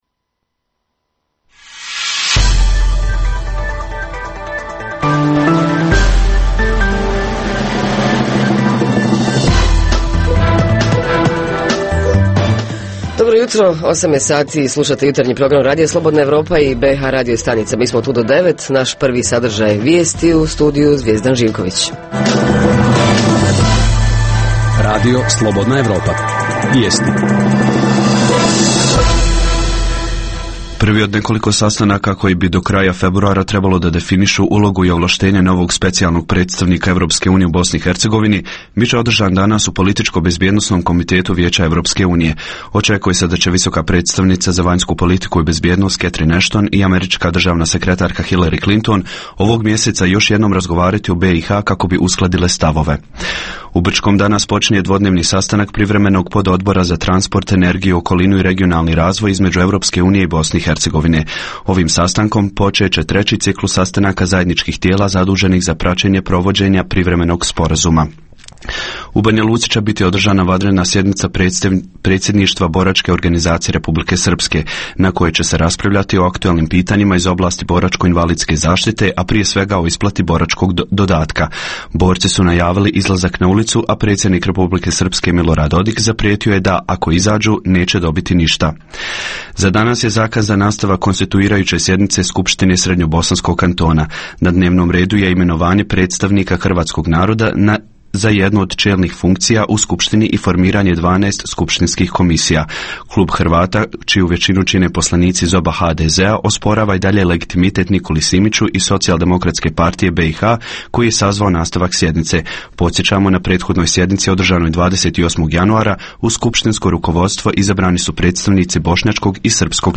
Reporteri iz cijele BiH javljaju o najaktuelnijim događajima u njihovim sredinama.
Uz to poslušajte vijesti i muziku.